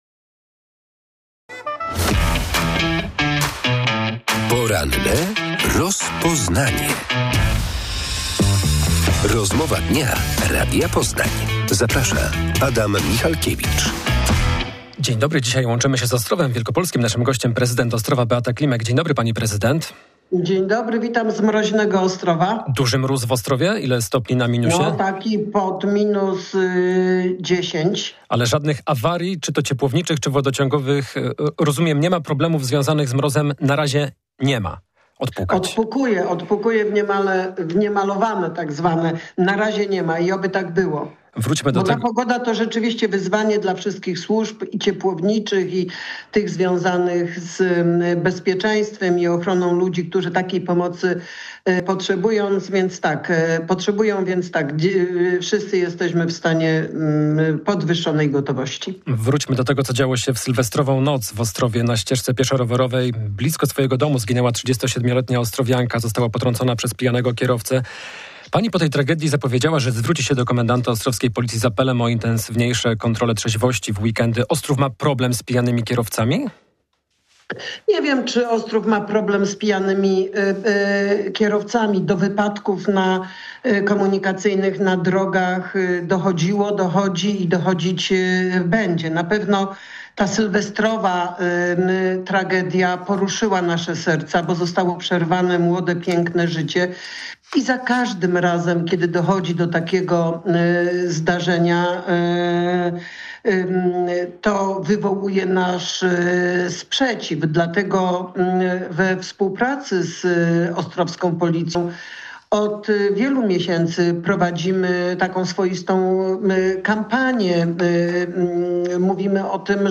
Ostrów Wielkopolski wciąż nie ma budżetu na ten rok. Prezydent miasta Beata Klimek w porannej rozmowie Radia Poznań zapowiedziała dziś, że w poniedziałek spotka się z będącymi w opozycji radnymi Koalicji Obywatelskiej.